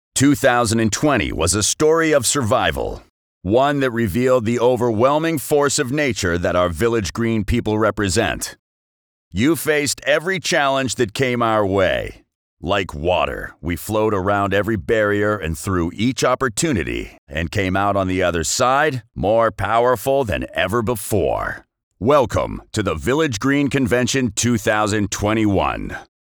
Male
Radio Commercials
0226authoritative_male_voice_over_village_green_story_of_survival.mp3